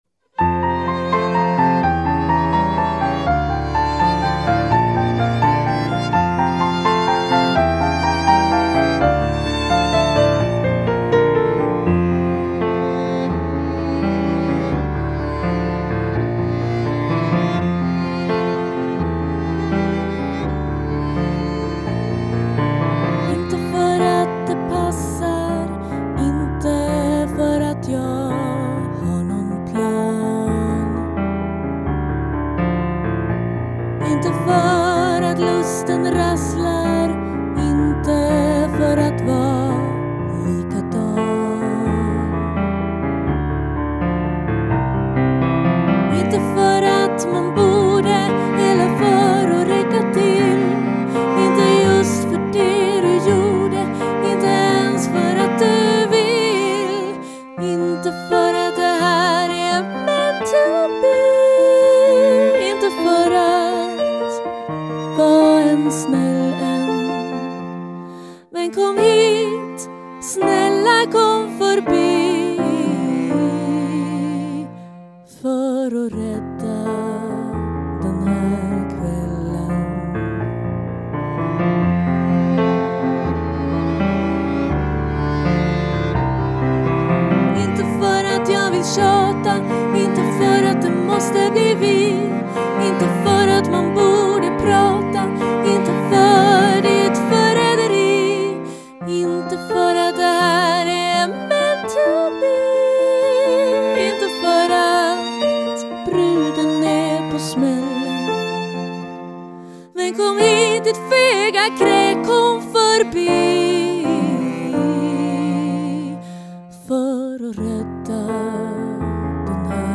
piano, dragspel, sång
Saxofon
Fiol
Trumprogrammering & synth
Cello